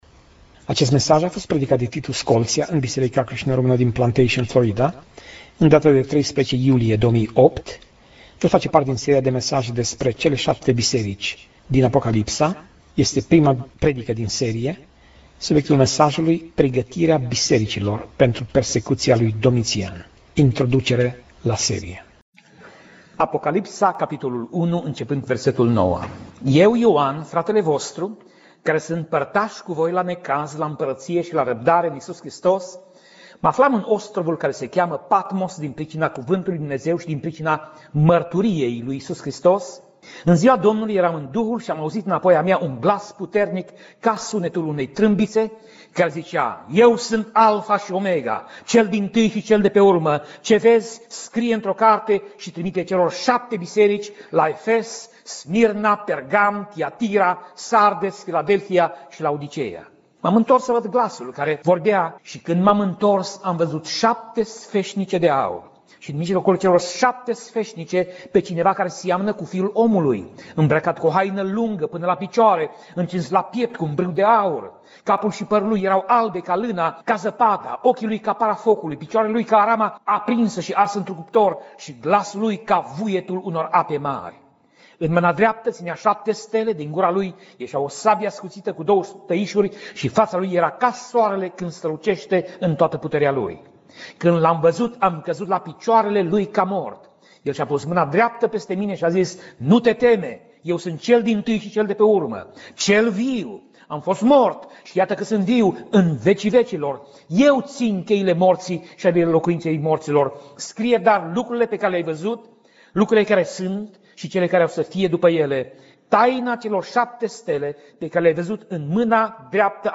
Pasaj Biblie: Apocalipsa 1:1 - Apocalipsa 1:20 Tip Mesaj: Predica